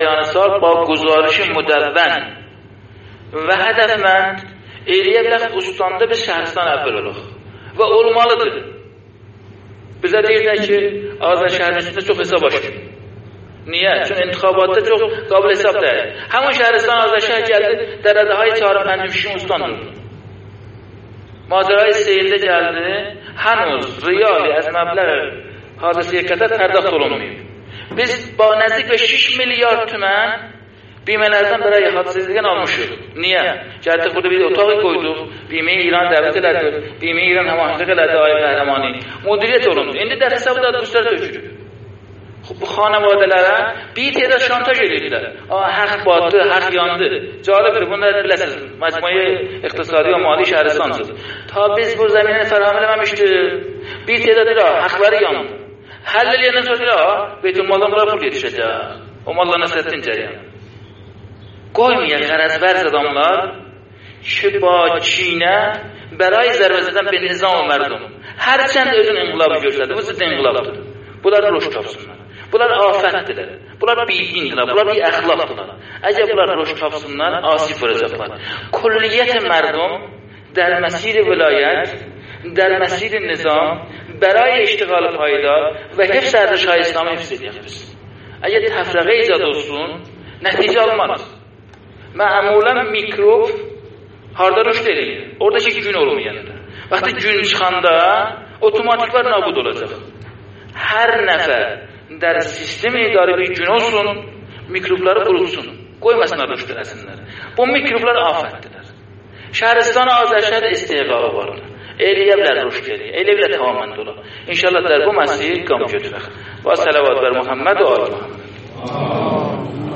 جلسه کارگروه اشتغال و سرمایه گذاری شهرستان آذرشهر در 21 خرداد برگزار شد در این جلسه اظهارات کوتاه فرماندار محترم در خصوص حادثه سیل در شهرستان آذرشهر و برخورد خبری رسانه‌های بیگانه و دشمنان ملت و نظام با تعبیر نادرست از سوی خبرنگار حاضر در جلسه دستمایه خبری رسانه‌های منتقد و بعضاً مخالف دولت قرار گرفت و اخبار کذبی در فضای مجازی به نقل از فرماندار محترم شهرستان آذرشهر در برخی کانال‌ها و سایت‌های خبری منتشر شد.